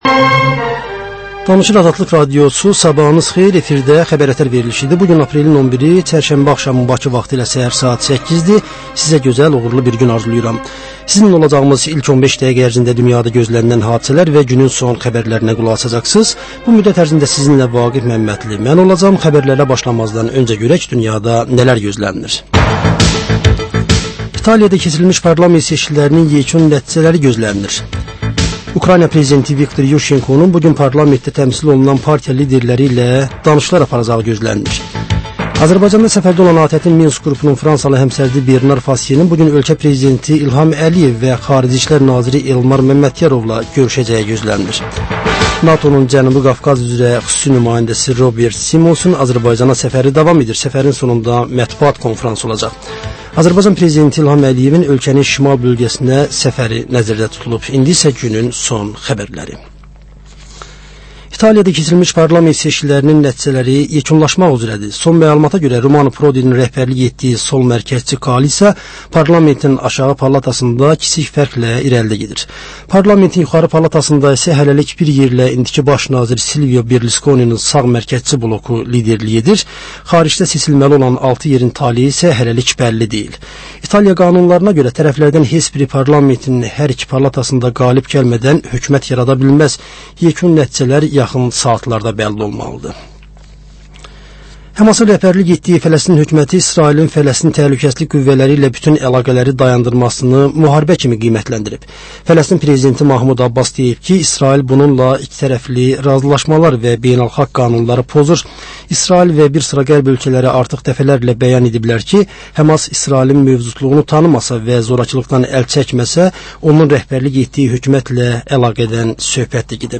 Səhər-səhər, Xəbər-ətər: xəbərlər, reportajlar, müsahibələr